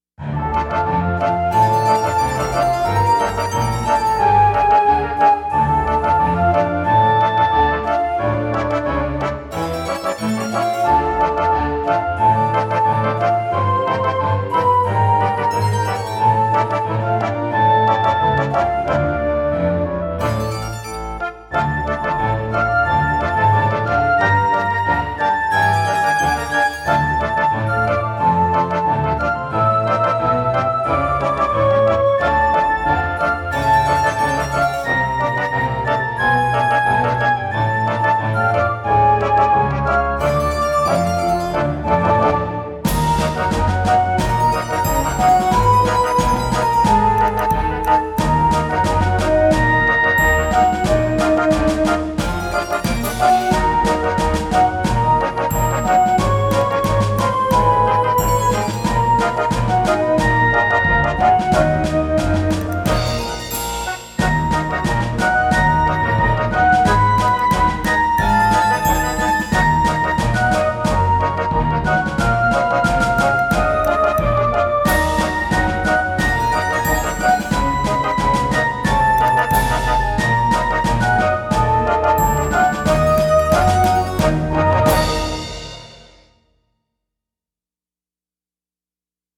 If Harry Potter was a DOS Adventure Game, I imagine it would sound something like this.
We remixed it into an orchestral piece the other day, and gave it the Roland XV-5080 /  Fantom XR / SRX Expansion Board treatment.